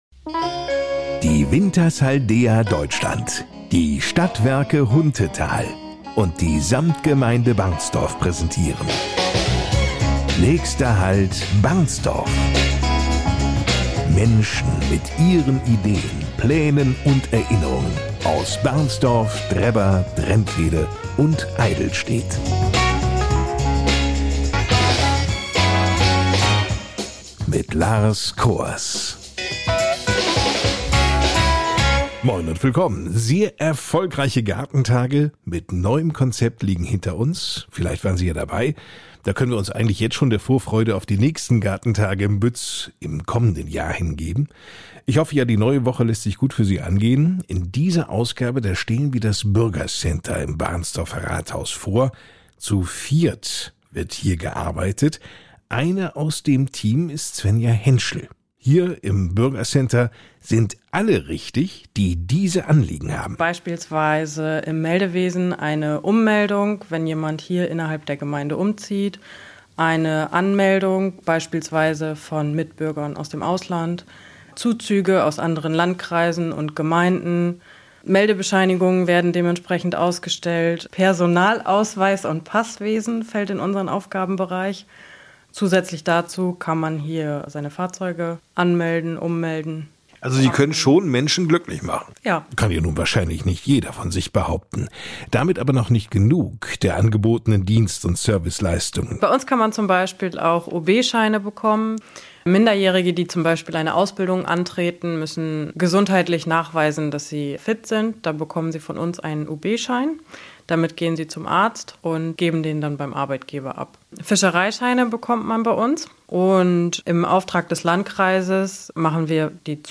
In jeder Ausgabe unserer Podcastreihe NÄCHSTER HALT BARNSTORF widmen wir uns einem Thema aus der Samtgemeinde Barnstorf und sprechen darüber mit Menschen aus Barnstorf, Drebber, Drentwede und Eydelstedt über ihre Ideen, Pläne oder auch Erinnerungen.
Die Podcast-Lokalradioshow